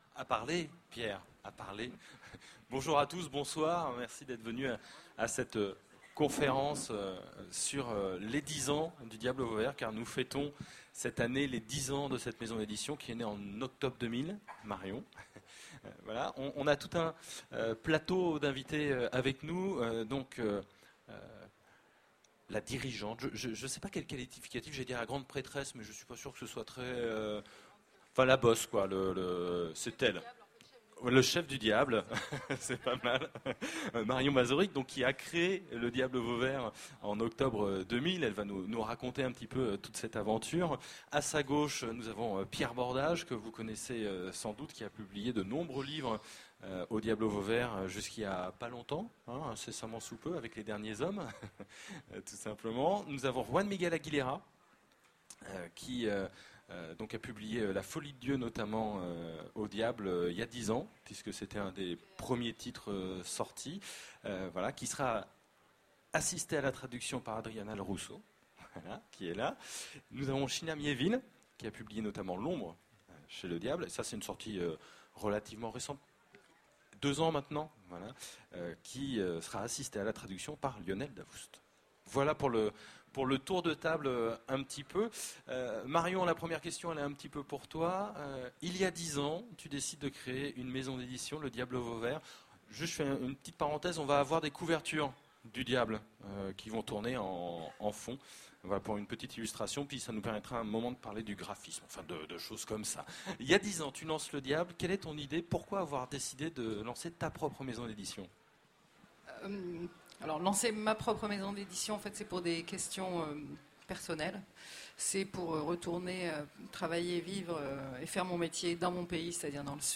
Voici l'enregistrement de la conférence sur les 10 ans du Diable Vauvert aux Utopiales 2010.